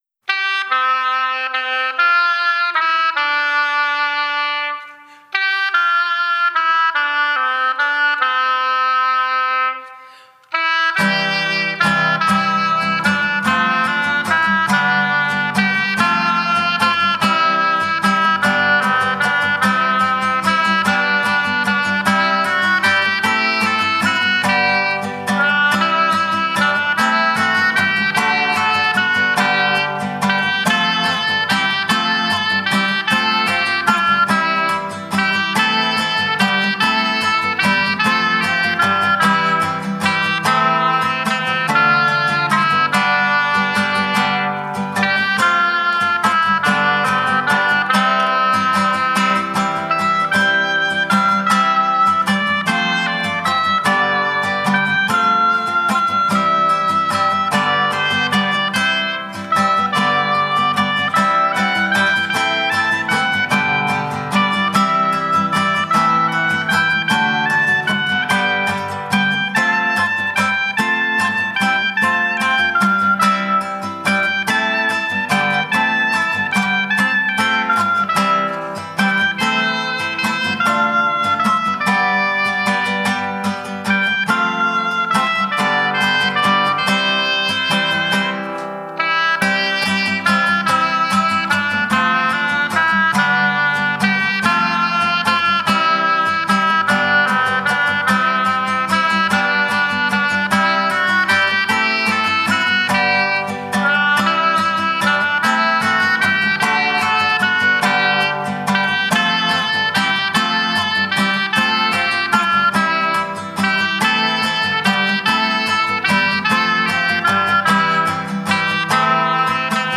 Instrumentalversion